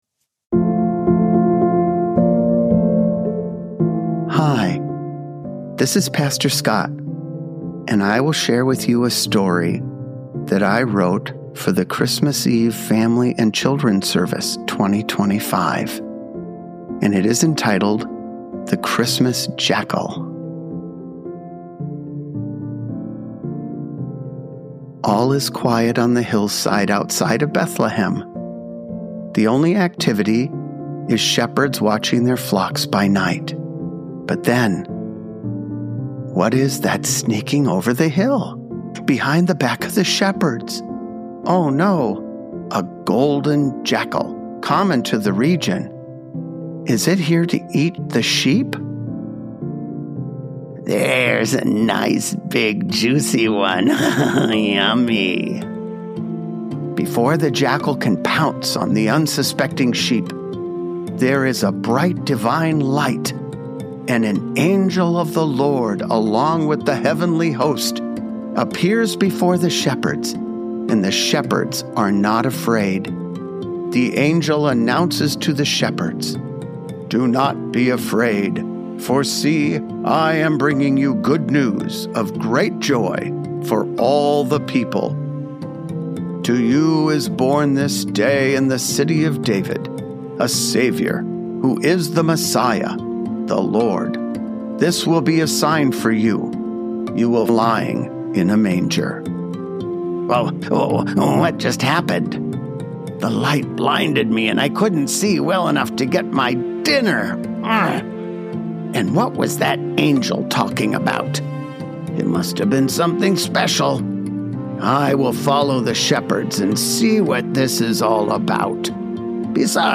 Help your children prepare for bed with our prayer and relaxation podcast.  Each podcast will feature calming music, Scripture and prayers to help children unwind from their day.